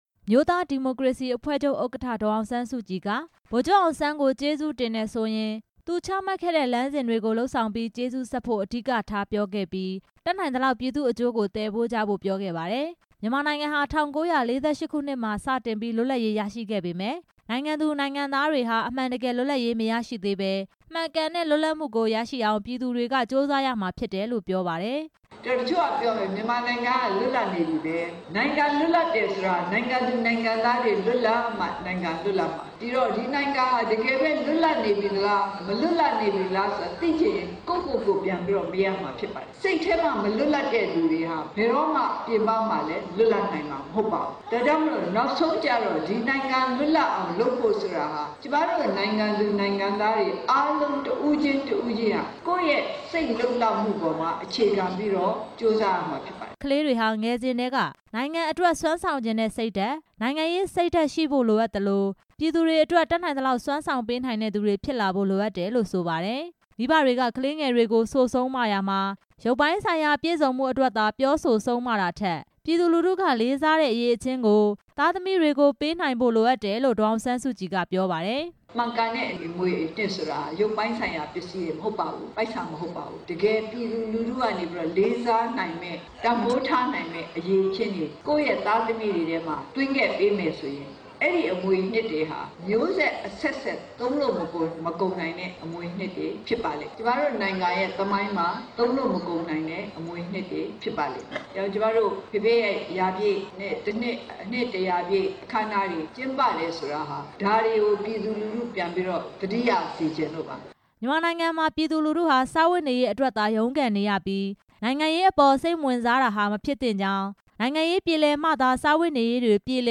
မန္တလေးတိုင်းဒေသကြီး ပျော်ဘွယ်မြို့နယ် ရွှေပြည်သာဘုရားဝင်းမှာ ဒီနေ့ကျင်းပတဲ့ ဗိုလ်ချုပ် နှစ်တစ်ရာပြည့်အကြို လူထုဟောပြောပွဲမှာ ဒေါ်အောင်ဆန်းစုကြည်က အခုလို ပြောကြားလိုက်တာပါ။